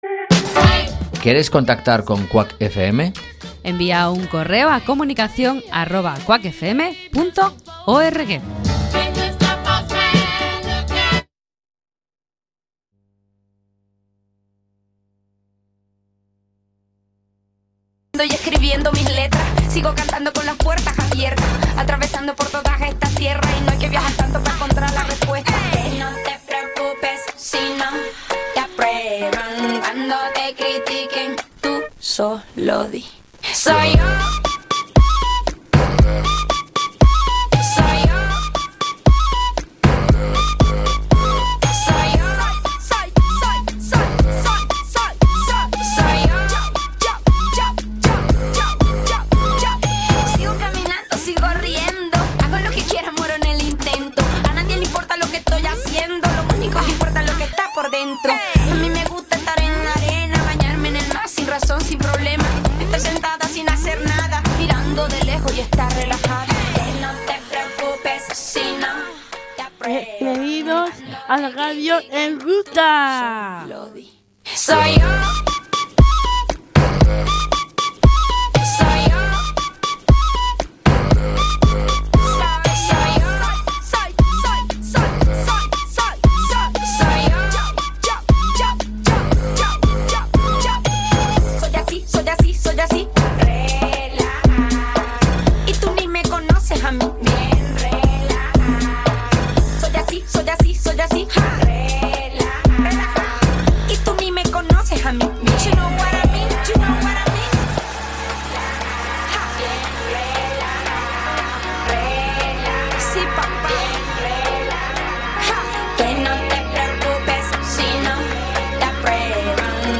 Radio en Ruta é o magazine radiofónico feito polo alumnado do programa formativo Ruta, unha formación dirixida a mozos e mozas con discapacidade intelectual da Fundación Amador de Castro.